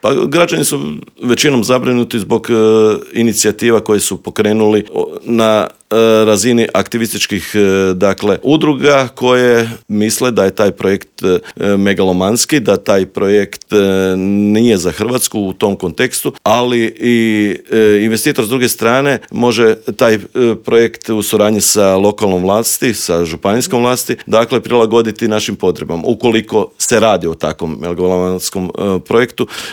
ZAGREB - U novom izdanju Intervjua Media servisa gostovao je predsjednika HDZ-a Novi Zagreb Istok i državnog tajnika u Ministarstvu poljoprivrede Tugomir Majdak s kojim smo prošli teme od gorućih problema u Novom Zagrebu, preko najavljenog prosvjeda u Sisku protiv industrijskih megafarmi i klaonice pilića pa sve do ovisnosti Hrvatske o uvozu hrane.